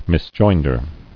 [mis·join·der]